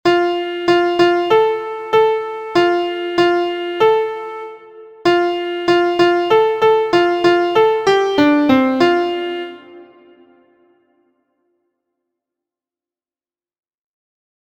• Origin: USA – Game Song
• Key: F Major
• Time: 4/4
• Form: ABCD
• Pitches: intermediate: So La Do Re Mi
• Musical Elements: notes: quarter, eighth; rests: quarter; two measure phrases, question and answer